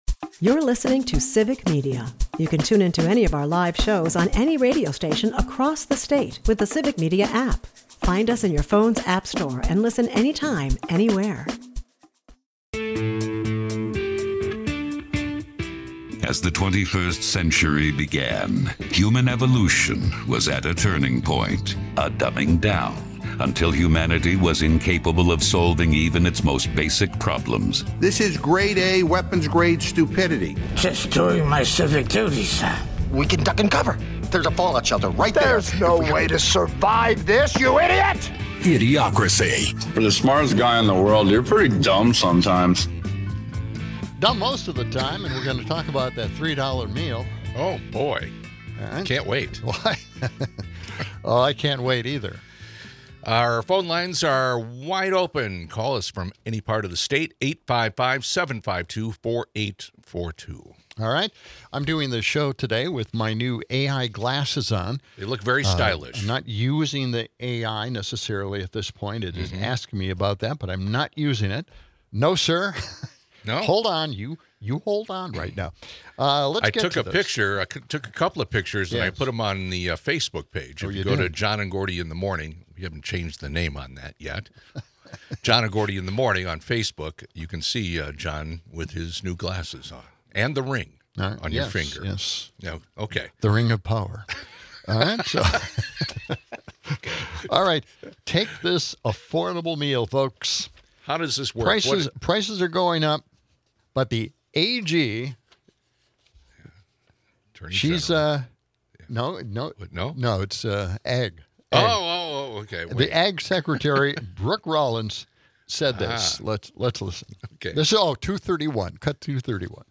call in to walk through how their protest went this afternoon